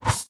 Select Scifi Tab 2.wav